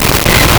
Player_Glitch [4].wav